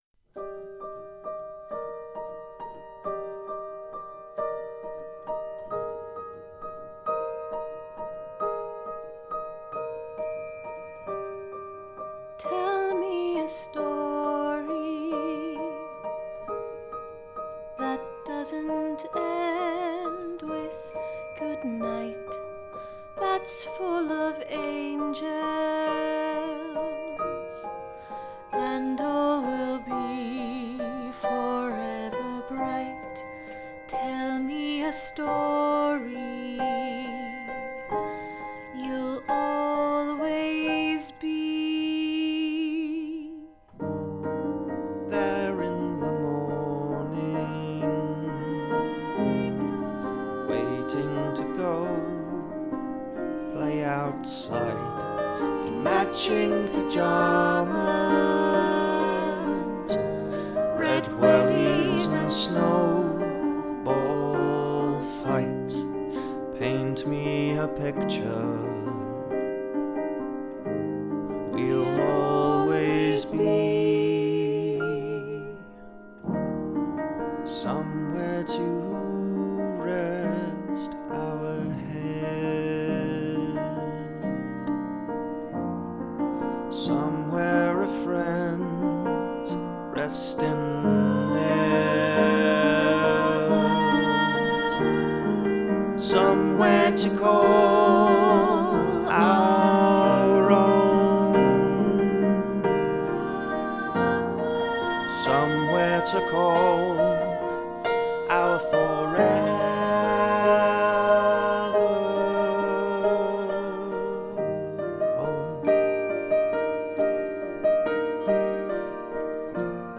Tutti - 3VGC Forever Home - Three Valleys Gospel Choir
Tutti – 3VGC Forever Home